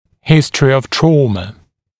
[‘hɪstrɪ əv ‘trɔːmə][‘хистри ов ‘тро:мэ]информация о перенесенной травме (травмах)